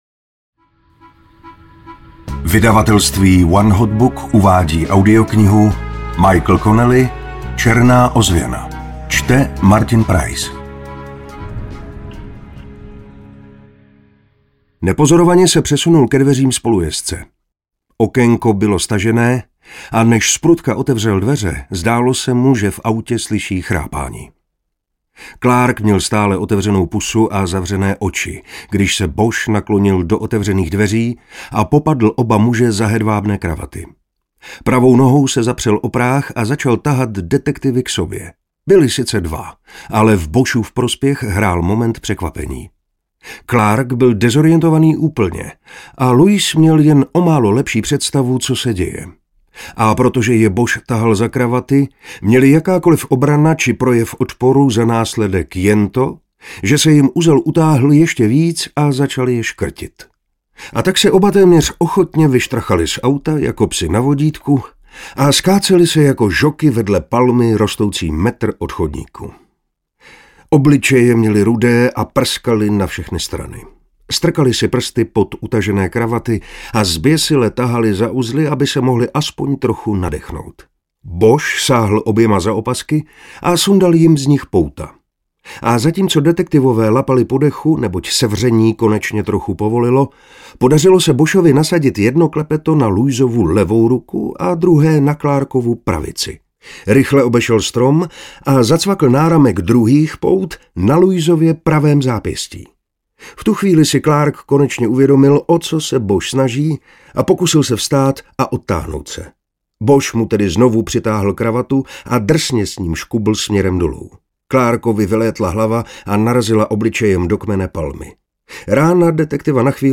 3x Bosch #1 audiokniha
Ukázka z knihy
• InterpretMartin Preiss